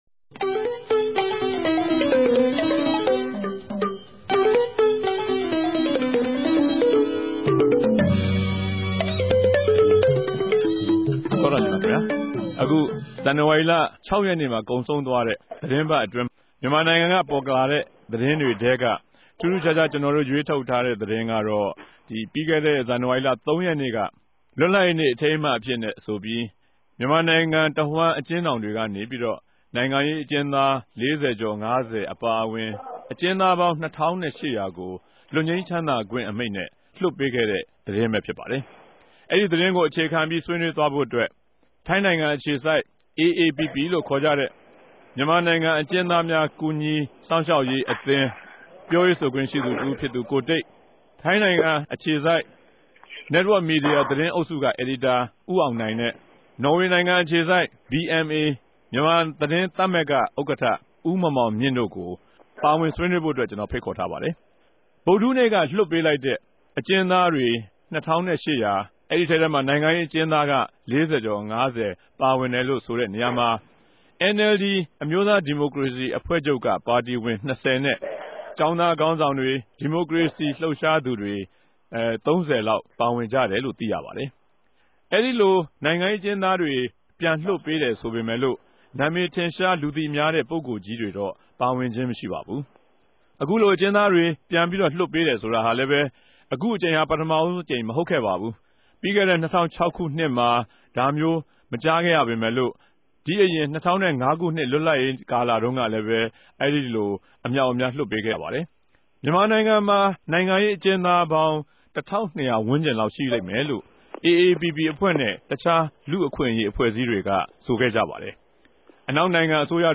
တပတ်အတြင်း သတင်းသုံးသပ်ခဵက် စကားဝိုင်း (၂၀၀၇ ဇန်နဝၝရီလ ၇ရက်)